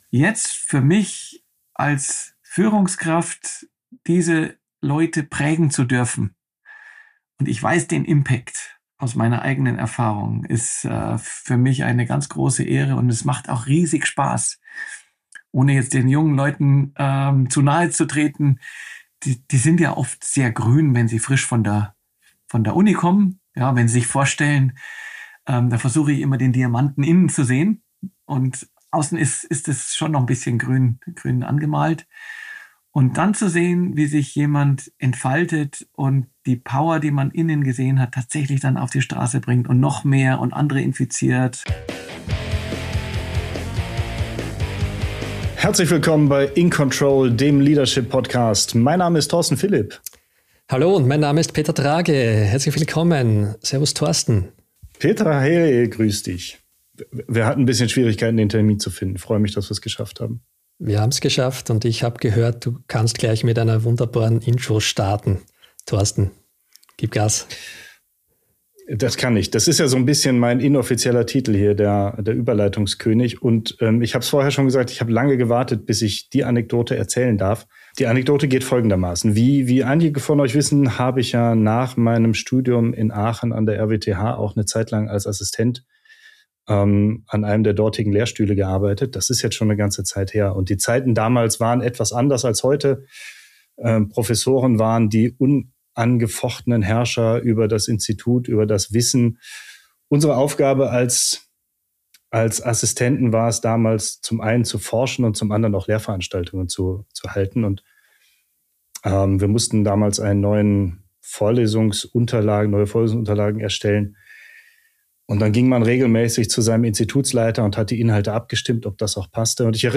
Ein ehrliches Gespräch über Leadership jenseits klassischer Hierarchien – und darüber, warum Forschungserfolg nicht nur von den besten Köpfen, sondern auch von der richtigen Führung abhängt.